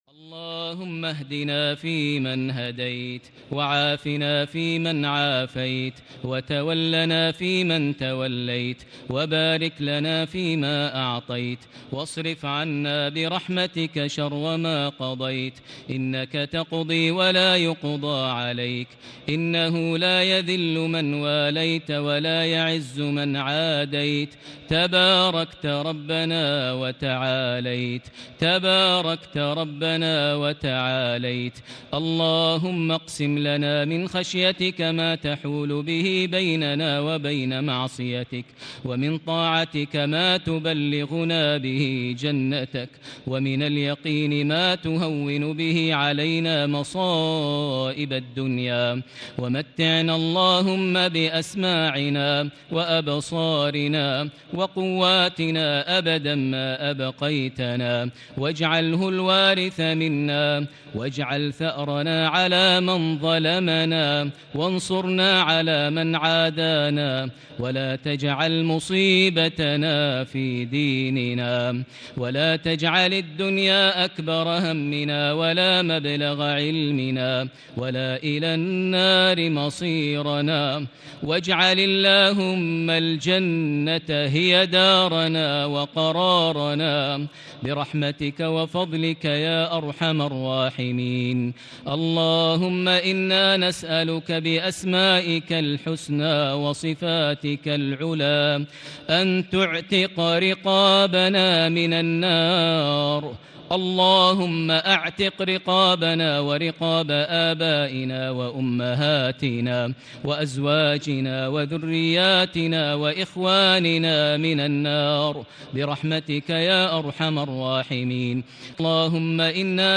دعاء القنوت ليلة 24 رمضان 1440هـ | Dua for the night of 24 Ramadan 1440H > تراويح الحرم المكي عام 1440 🕋 > التراويح - تلاوات الحرمين